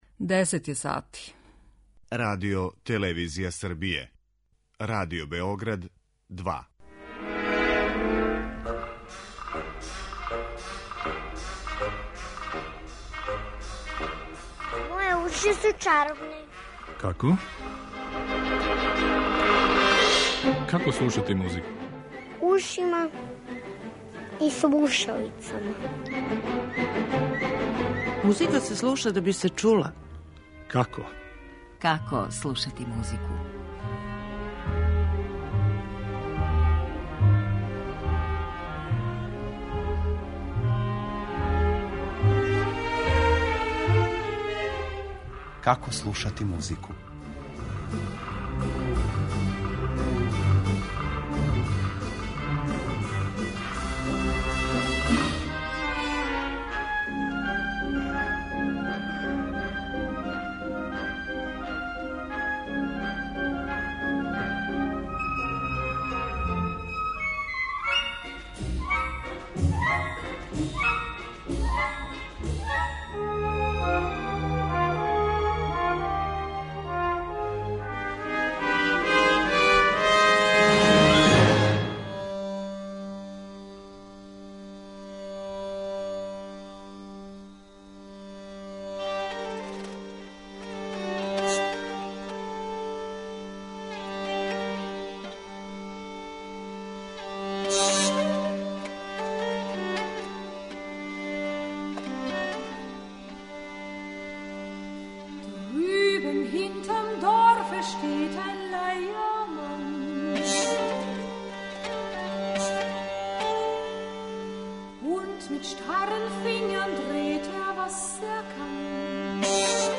Међу музичким примерима биће и необично извођење уз пратњу фолклорног инструмента познатог као харди-гарди.